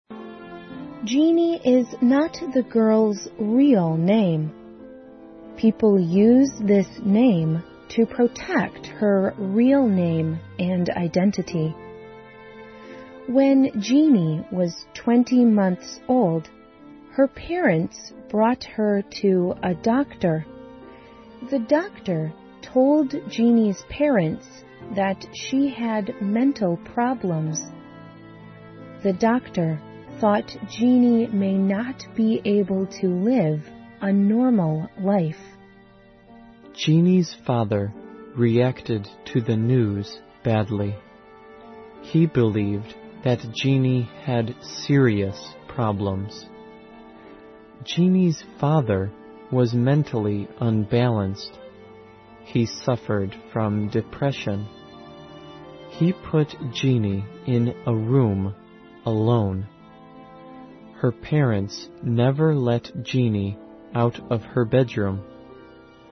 环球慢速英语 第569期:杰妮的故事(2)